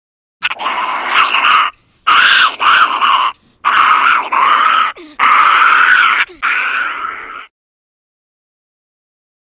Monkey ( Unknown ) Screams. Series Of Raspy, Angry Screams With Snort Inhales. Close Perspective.